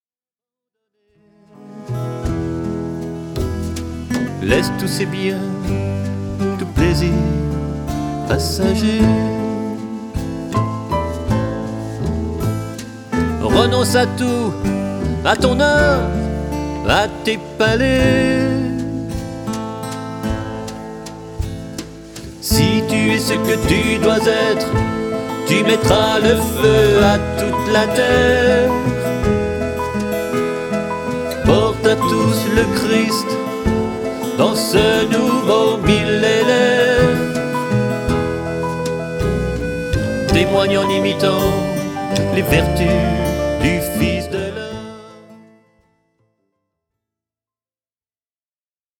Trois chansons (pistes 5, 11 et 16) illustrent cet album.